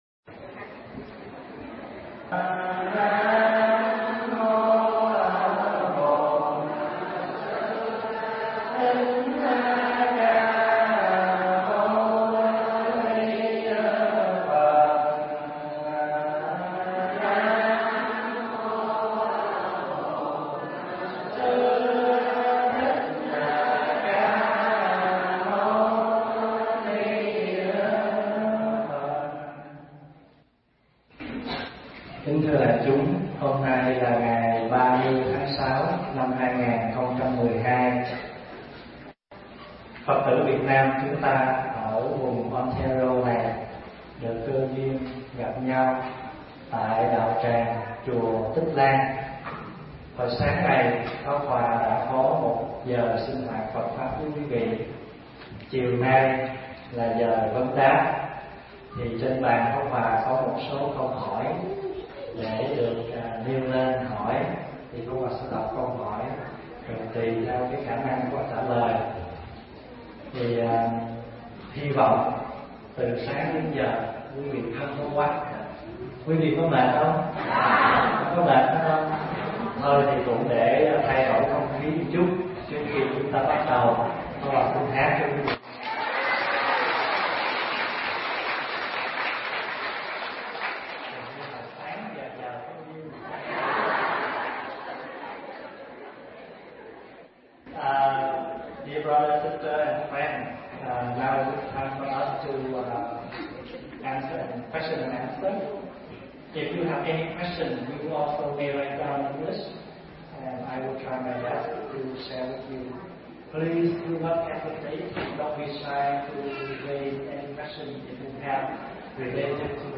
thuyết giảng tại Chùa Tích Lan, mừng xuân di lặc